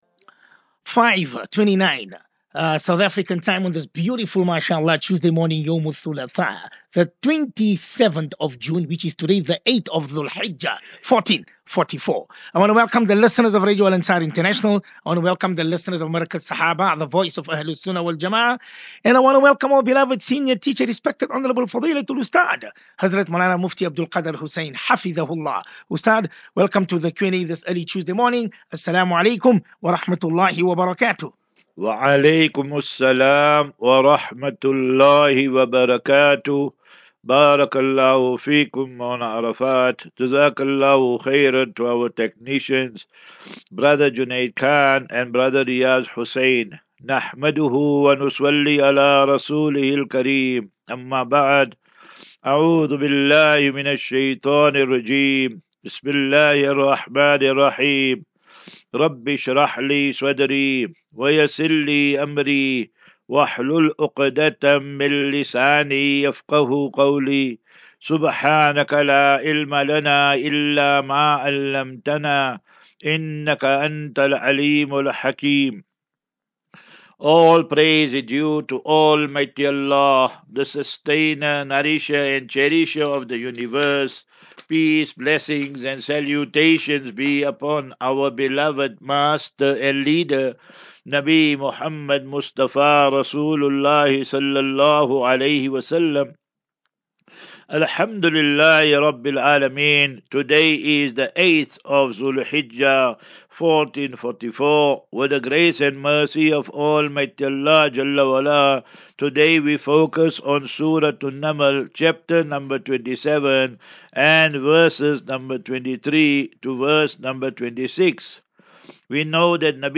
As Safinatu Ilal Jannah Naseeha and Q and A 27 Jun 27 June 23 Assafinatu